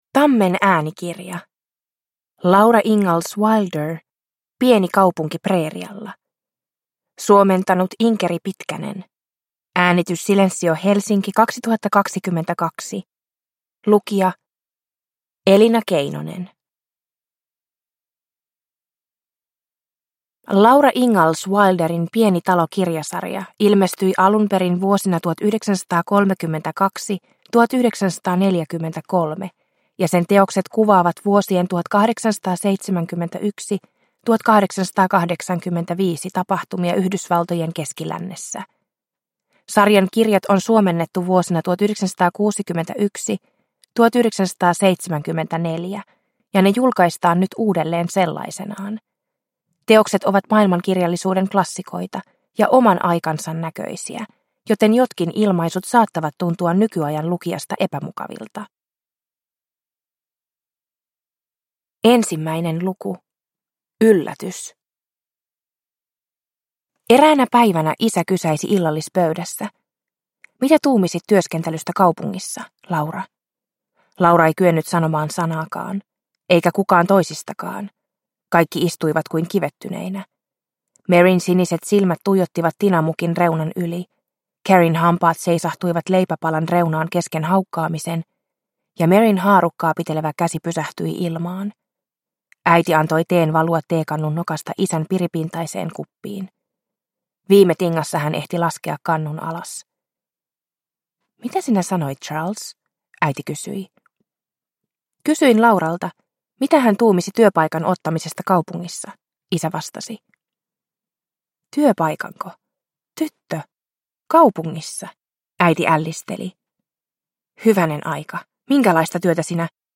Pieni kaupunki preerialla – Ljudbok – Laddas ner